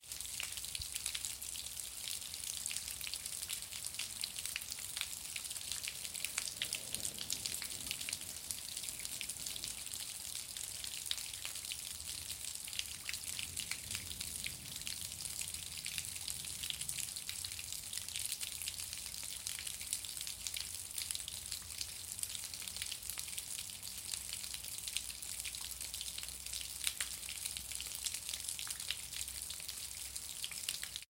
sfx_envi_water_dripping.mp3